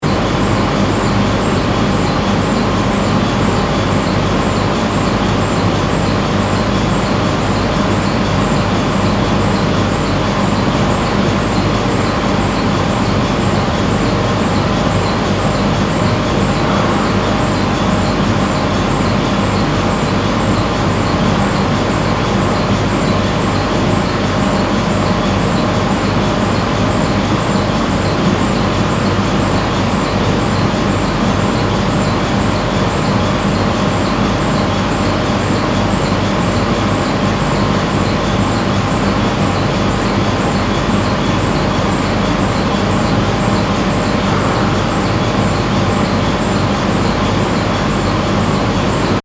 Soundscape: La Silla NTT SOFI
Cooling liquid and gas pumps are the most dominant sound in SOFI’s room, inside the NTT telescope building. SOFI is the infrared spectrograph and imaging camera on the NTT.
Soundscape Mono (mp3)